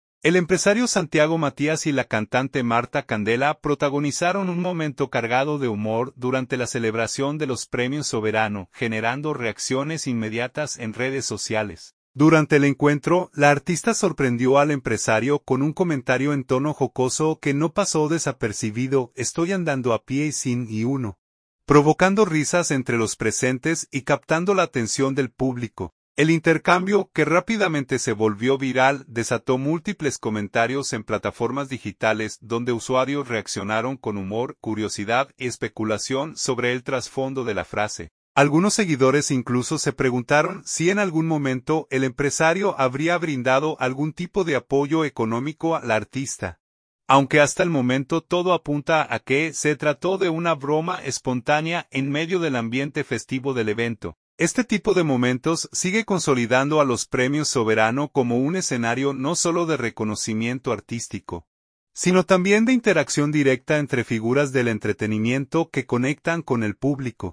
Santo Domingo.– El empresario Santiago Matías y la cantante Martha Candela protagonizaron un momento cargado de humor durante la celebración de los Premios Soberano, generando reacciones inmediatas en redes sociales.
“Estoy andando a pie y sin ni uno”, provocando risas entre los presentes y captando la atención del público.